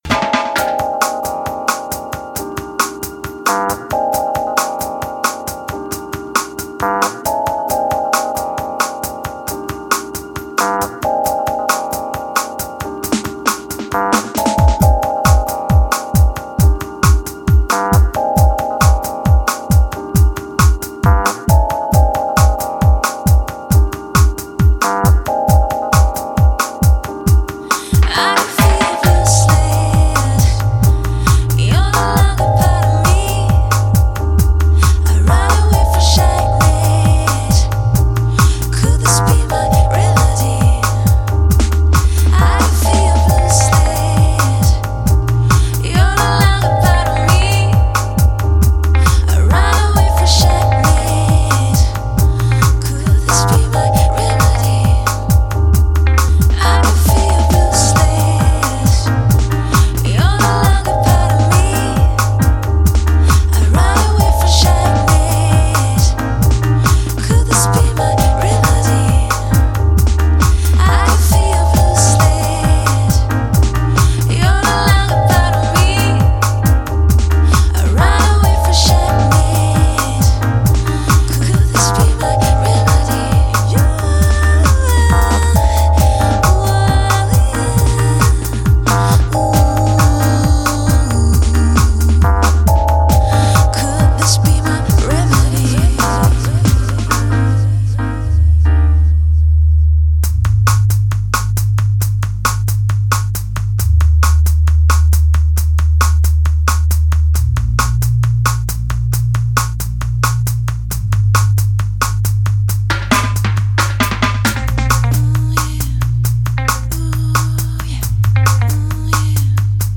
专辑类型：Lounge